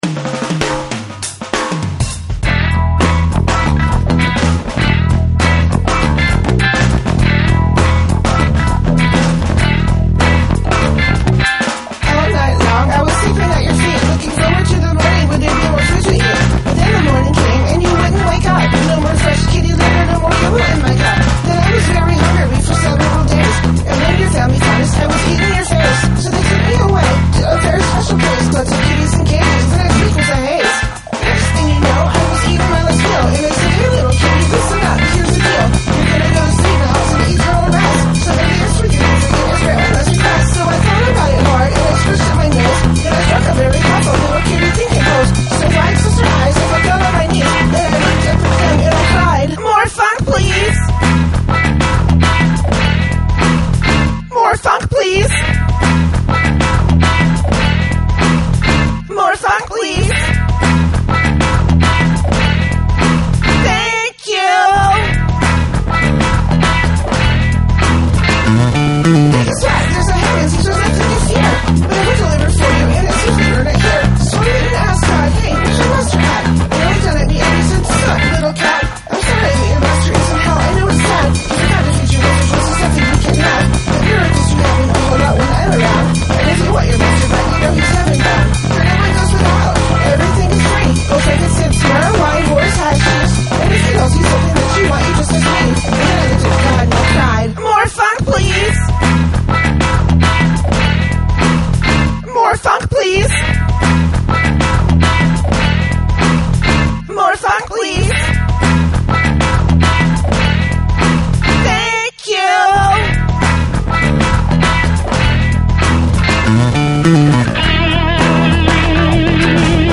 Please check it out and help me figure out how to get a more high fi sound. I wrote and performed it too (except the drum loops), so feedback on the song structure, performance, etc., is also appreciated.